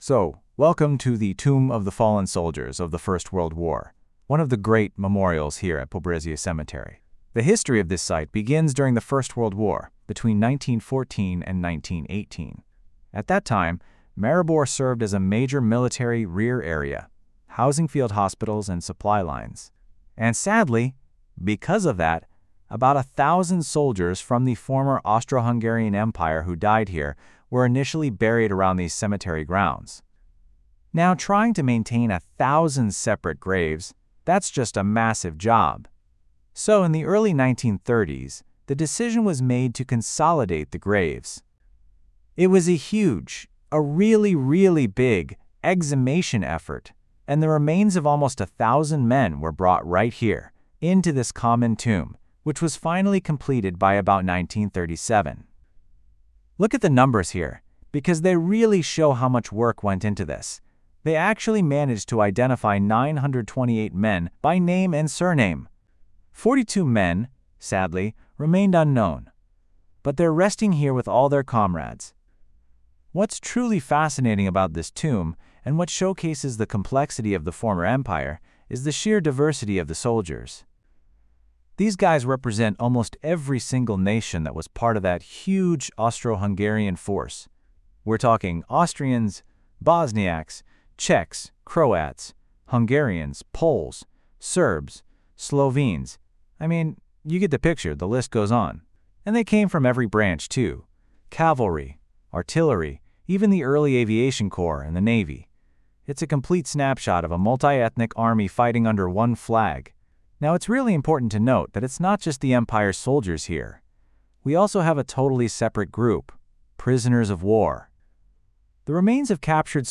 Tomb of the Fallen Soldiers of the First World War (audio guide) 2025.mp3